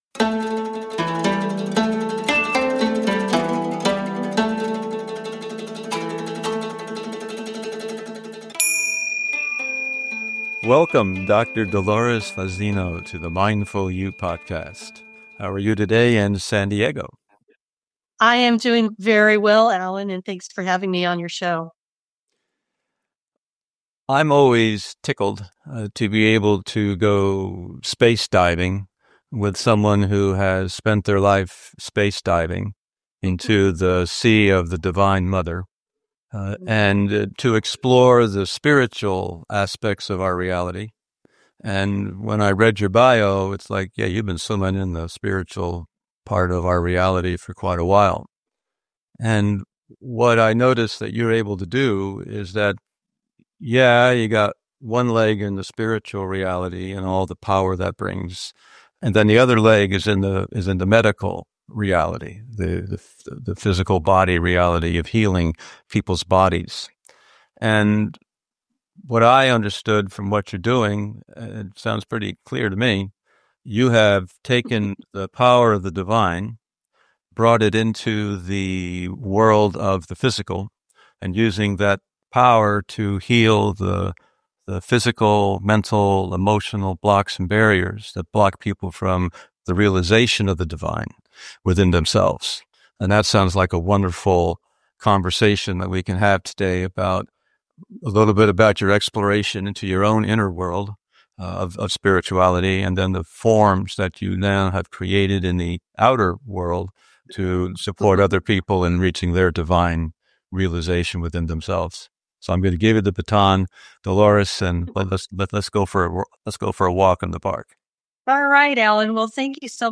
They discuss embodiment, mindful activities, and the power of words. Tune in for an inspiring conversation on self-discovery and healing.